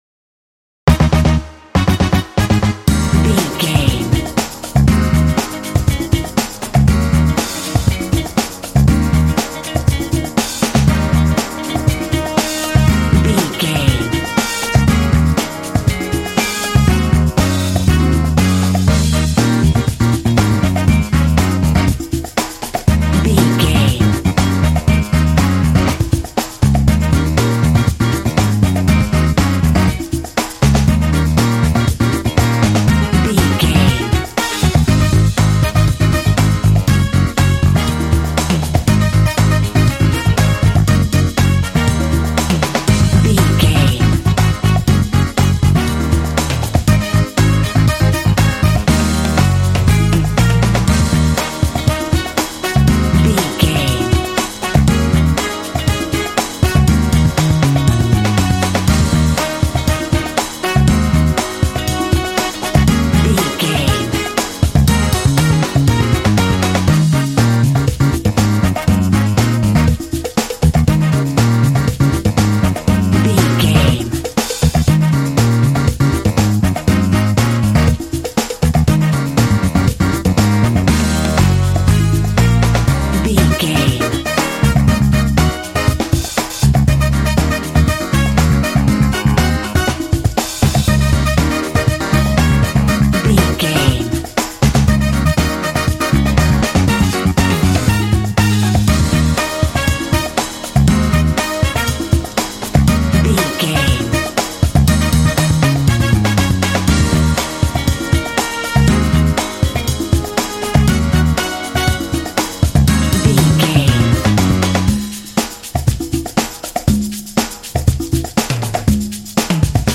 Uplifting
Aeolian/Minor
E♭
groovy
energetic
percussion
drums
piano
electric guitar
bass guitar
brass